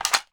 ak74_magout_empty.wav